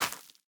Minecraft Version Minecraft Version snapshot Latest Release | Latest Snapshot snapshot / assets / minecraft / sounds / item / plant / netherwart2.ogg Compare With Compare With Latest Release | Latest Snapshot
netherwart2.ogg